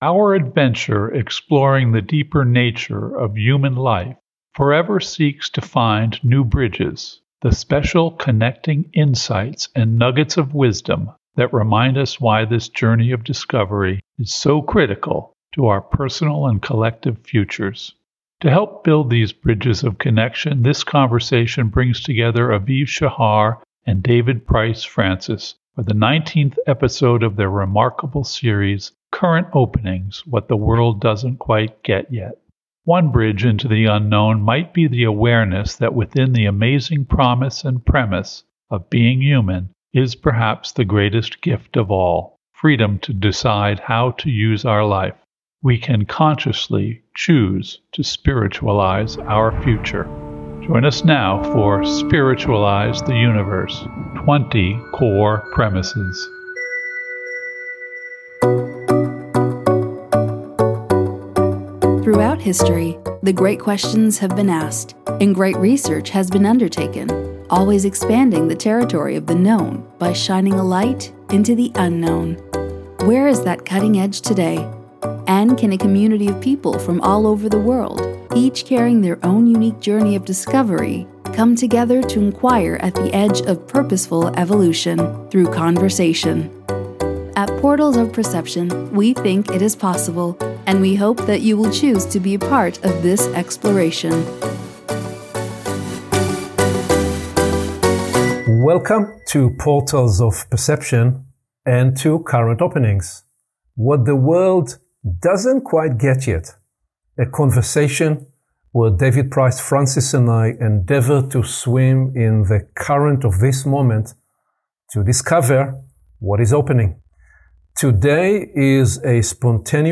This conversation is nothing short of transformational. We dive into how our emotional state shapes our biology, the role of the heart in regulating our ner…